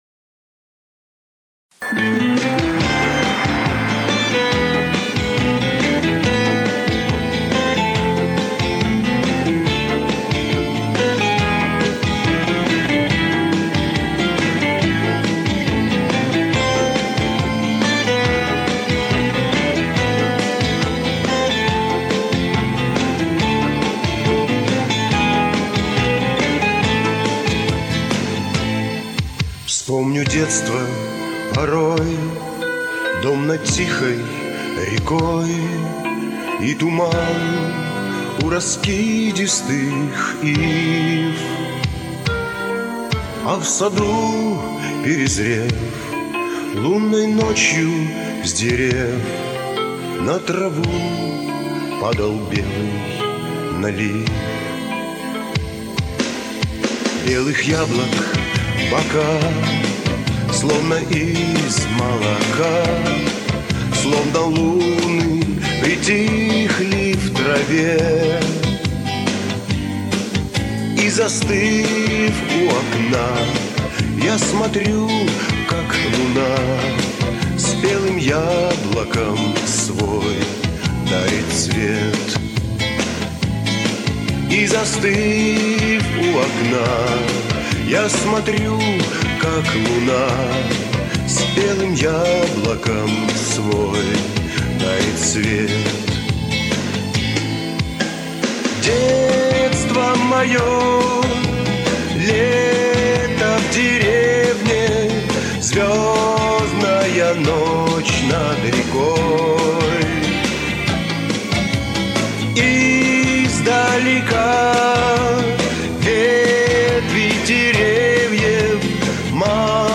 лирические песни